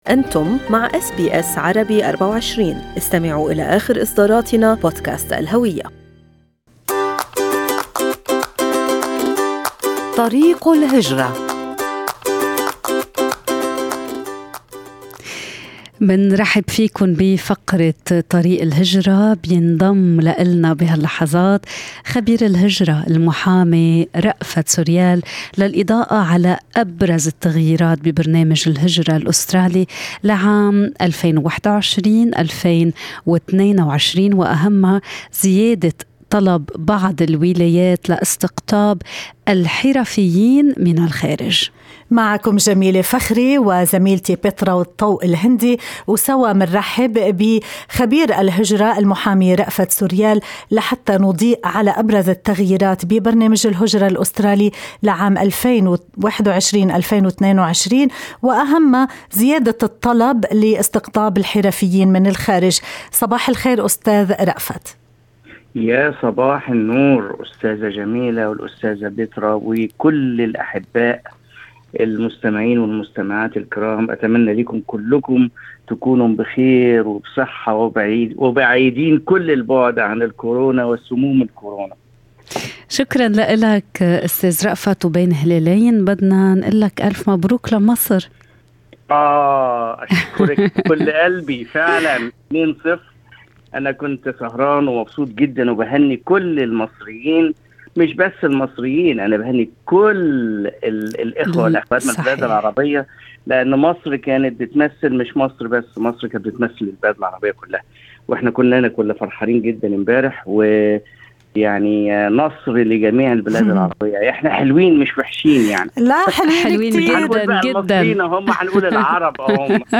استضاف برنامج Good morning Australia خبير الهجرة المحامي